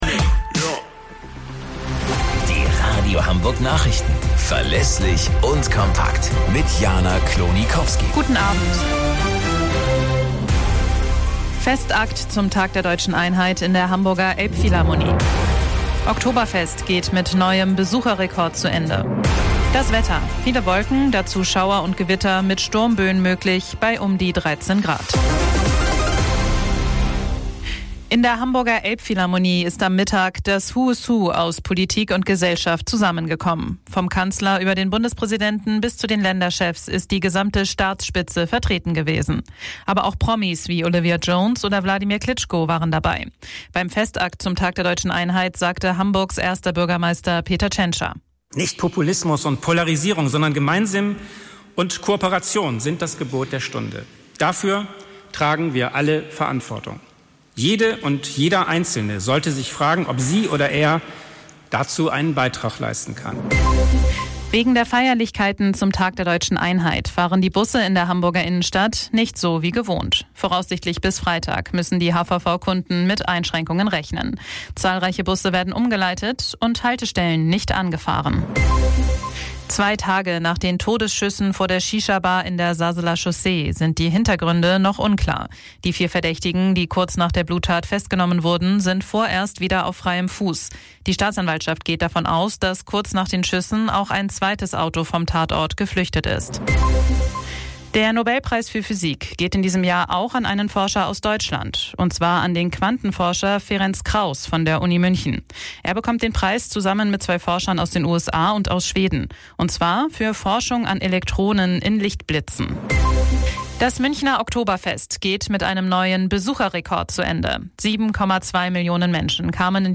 Radio Hamburg Nachrichten vom 04.10.2023 um 03 Uhr - 04.10.2023